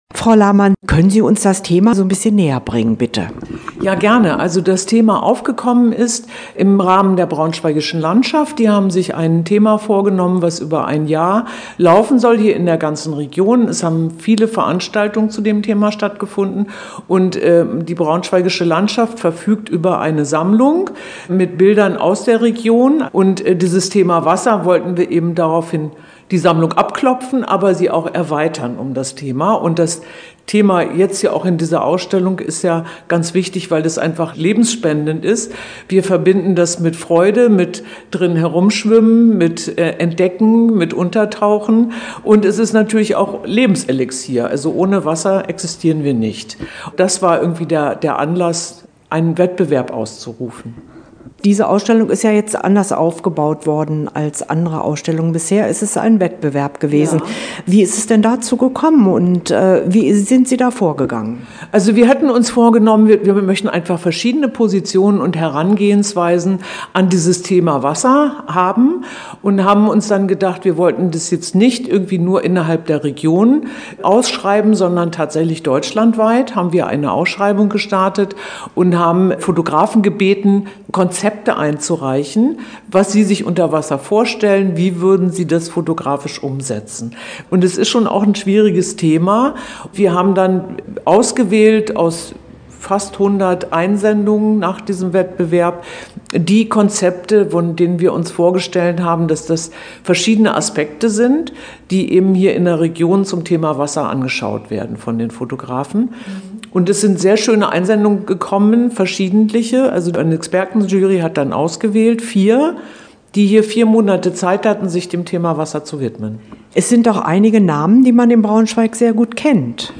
Interview-Kulturlandschaften-Wasser.mp3